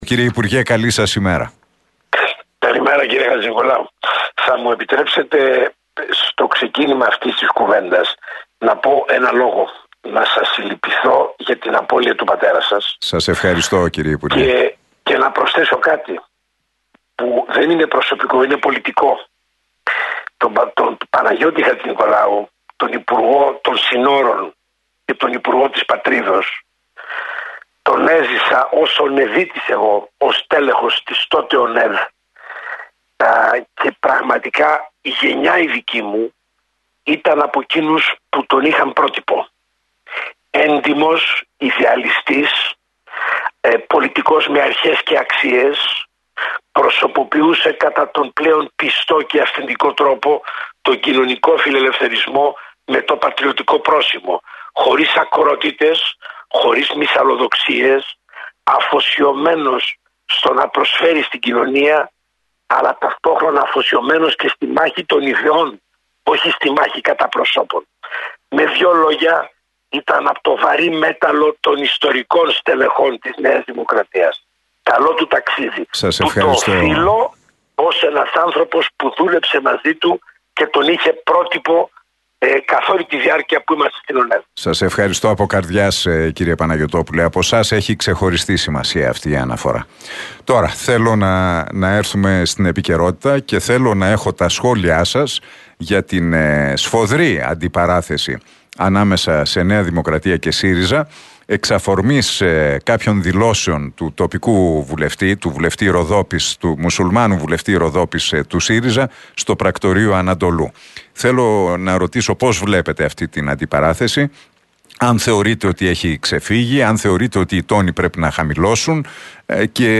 «Ξέροντας καλά τα θέματα της Θράκης, είναι λεπτά τα ζητήματα και ευαίσθητα για να γίνονται πεδίο σύγκρουσης και μάλιστα προεκλογικής σε πολύ υψηλούς τόνους των ελληνικών κομμάτων» δήλωσε ο πρώην υπουργός Άμυνας, Πάνος Παναγιωτόπουλος, μιλώντας στην εκπομπή του Νίκου Χατζηνικολάου στον Realfm 97,8.